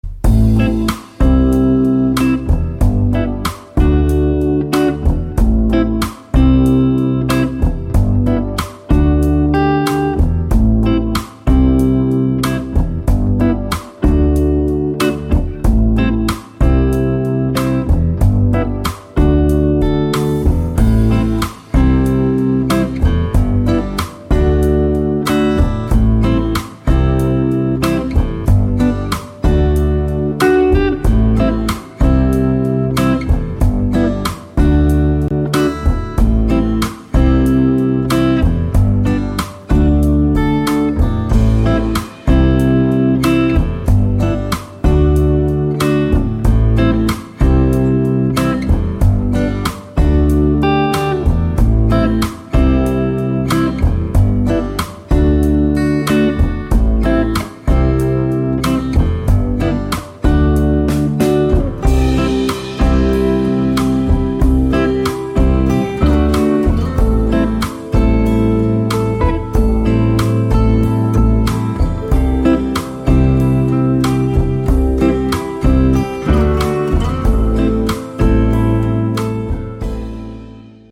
Kiitokset, uutta on tulilla, menee varmaan rootsin raameihin hitaana mollibluesina hyvin.
rs470_tausta.mp3